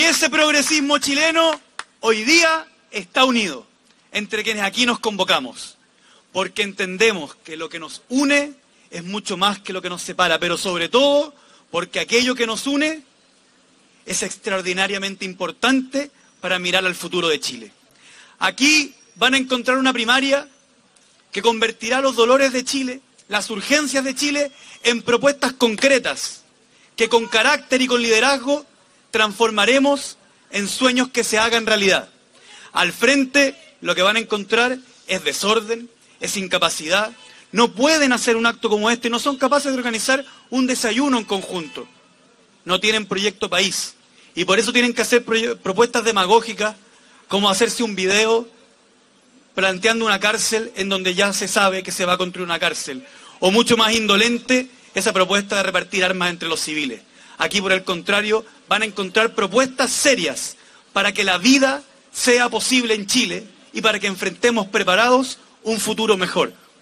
El diputado Gonzalo Winter, candidato del Frente Amplio, valoró la unidad como símbolo de “carácter y liderazgo” y cuestionó “el desorden y la incapacidad” de la oposición, un sector que por estos días “no es capaz de organizar un desayuno en conjunto”.